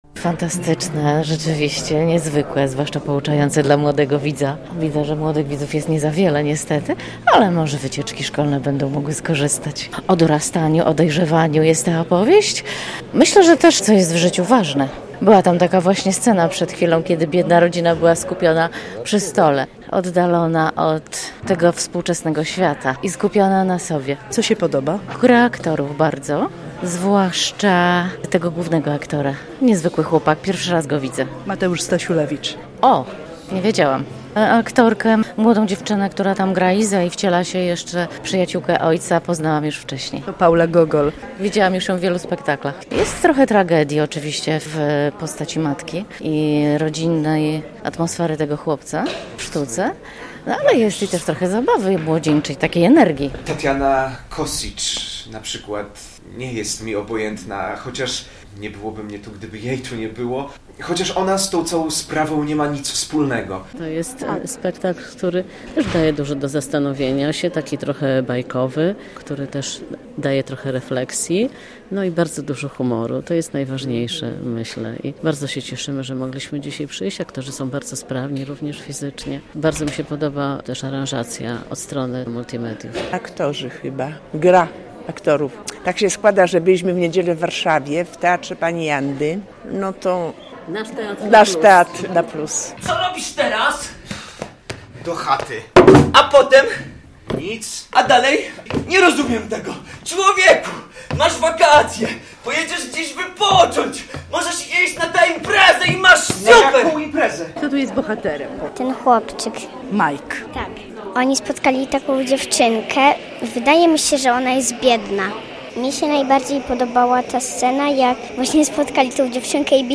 Wrażenia po premierze spektaklu "Tschick" - relacja